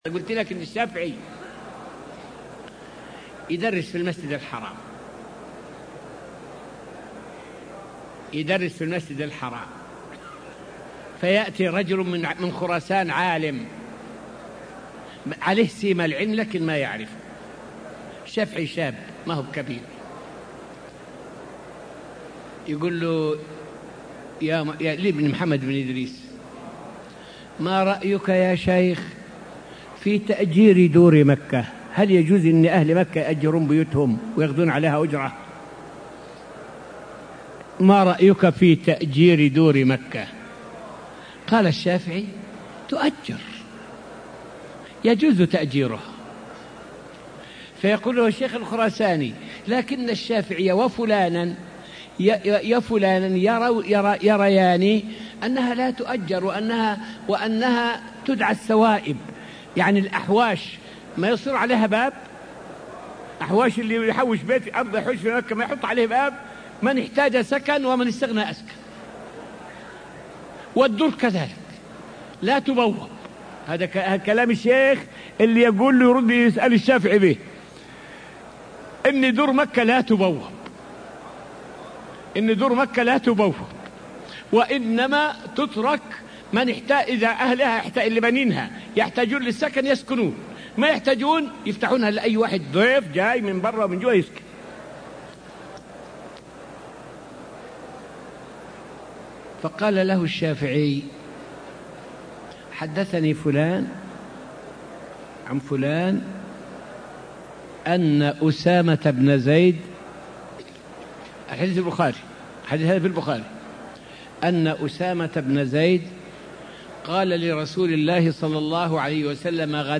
فائدة من الدرس التاسع من دروس تفسير سورة البقرة والتي ألقيت في المسجد النبوي الشريف حول بيان بعض النكات الحديثية المتعلقة بحديثي الجهنية والغامدية.